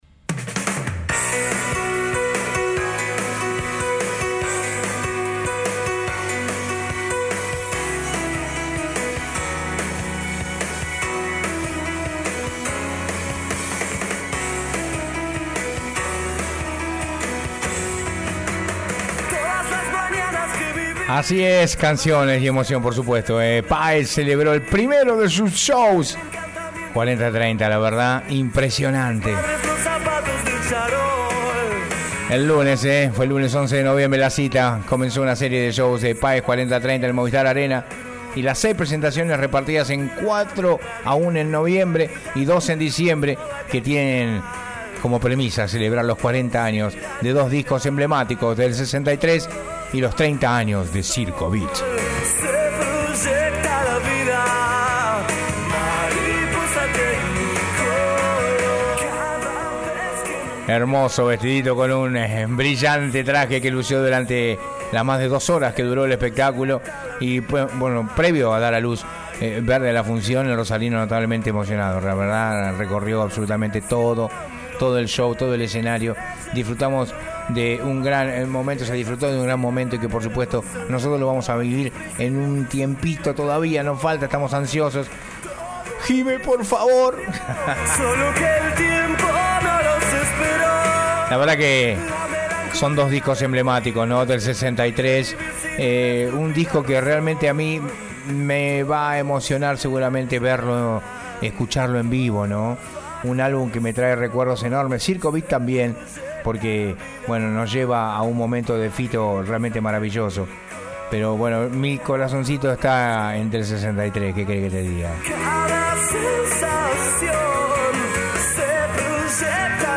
Fito Páez volvió con un gran show y te lo contamos en acá rock por radio cristal 94.9
FITO-PAEZ-MOVISTAR-ARENA-INFORME-CRISTAL.mp3